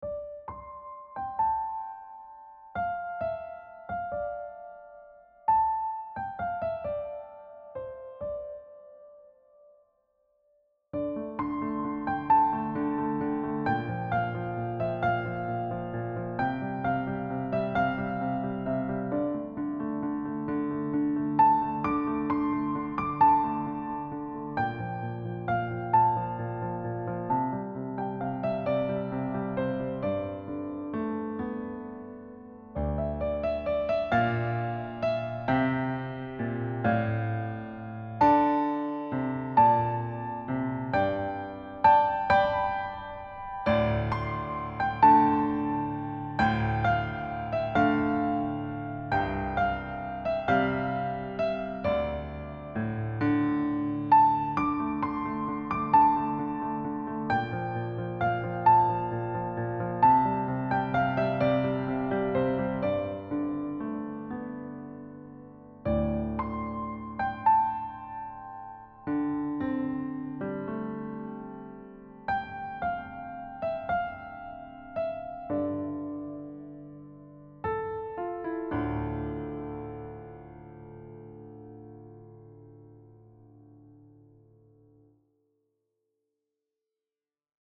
Key: C minor
Time Signature: 4/4, BPM ≈ 92